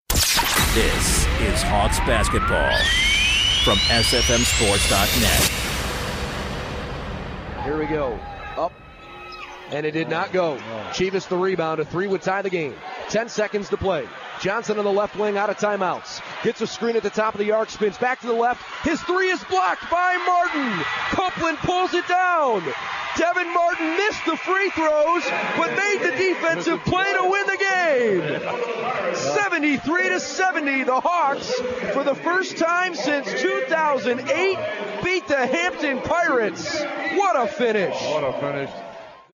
UMES Game Winning Call vs Hampton